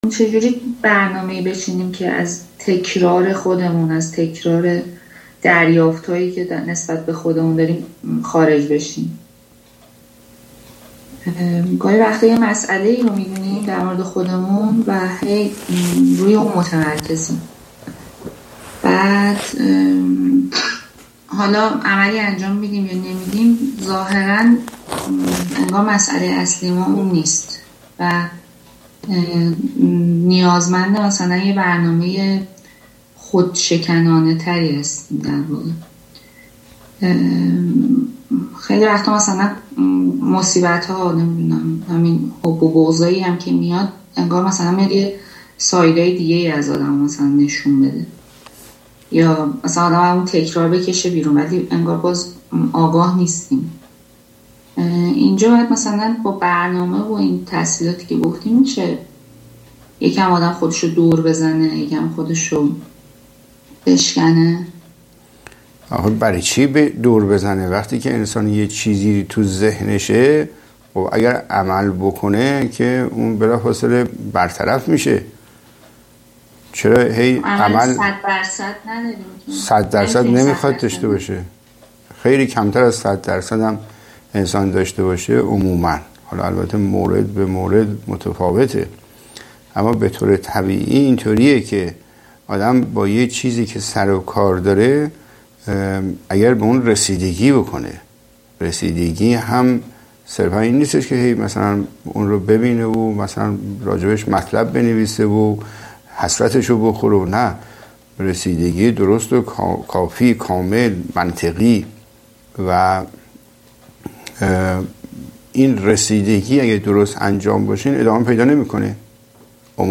شماره: 35 تاریخ : 1404-10-06 ساعت شروع : از 08:00:00 تا 10:00:00 فایل های جلسه : بازه زمانی: شماره : 351 متن : گفت‌وگو